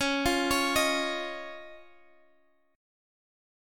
Dbadd9 Chord
Listen to Dbadd9 strummed